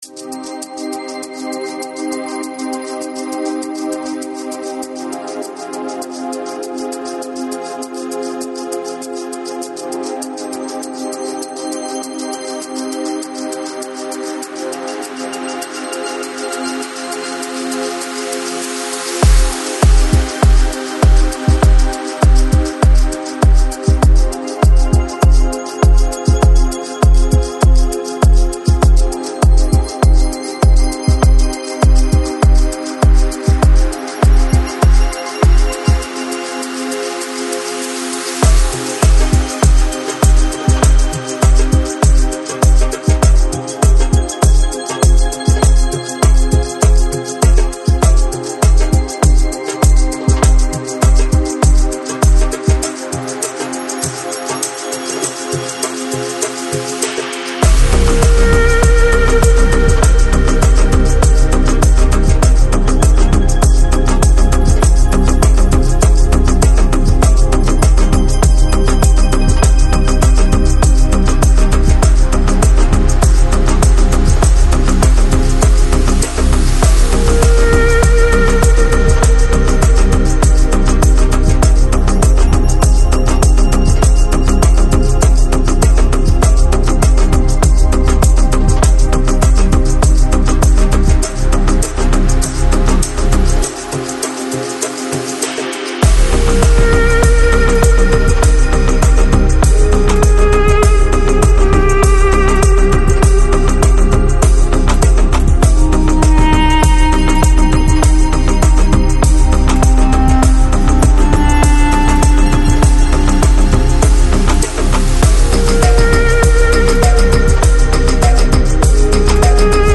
Жанр: Organic House, Progressive House